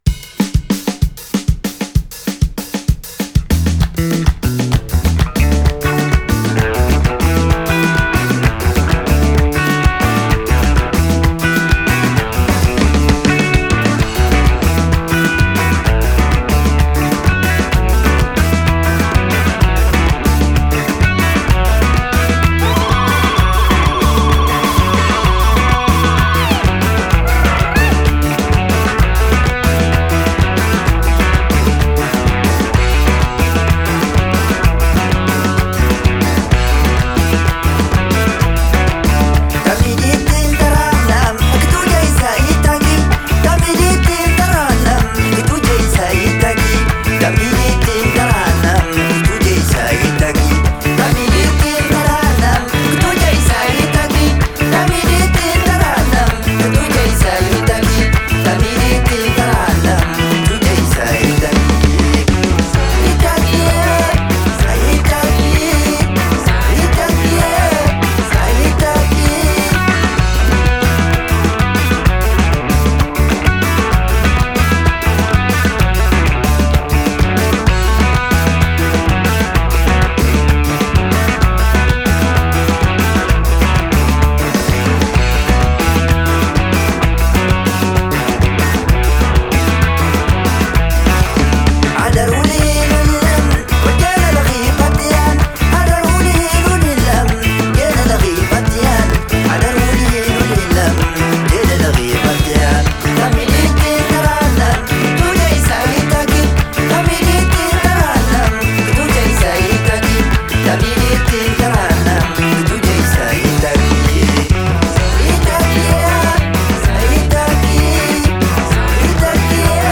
Genre: Desert Blues, Folk, World